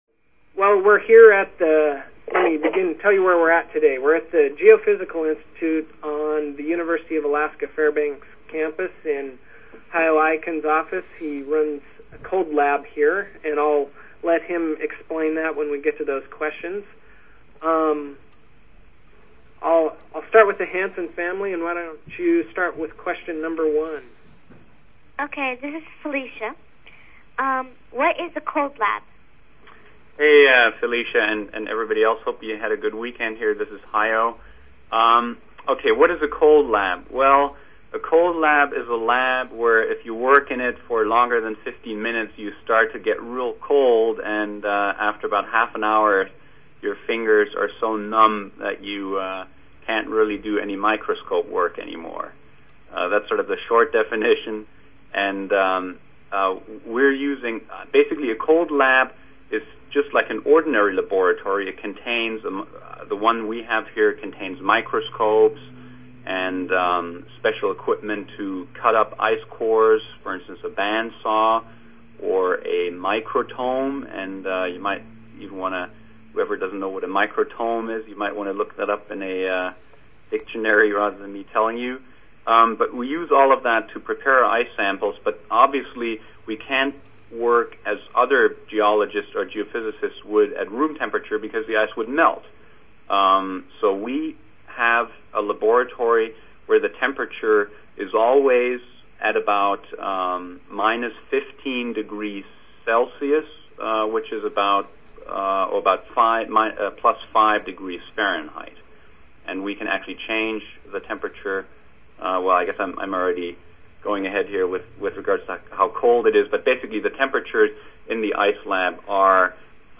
Audio Conference